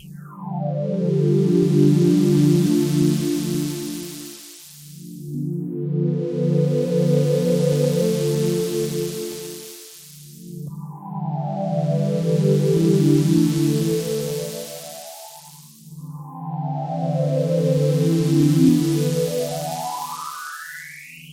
Tag: 90 bpm Electronic Loops Synth Loops 3.59 MB wav Key : Unknown